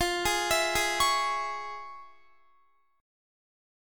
Listen to FmM7#5 strummed